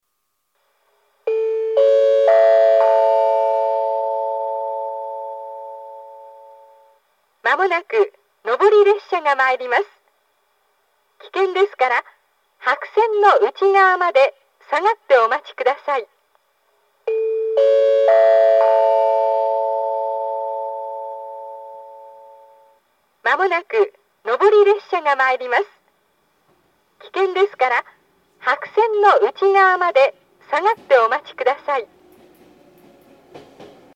接近放送は接近表示機とホーム軽井沢寄りにあるスピーカーから、発車ベルは電子電鈴から流れます。
２番線接近放送
miyota-2bannsenn-sekkinn.mp3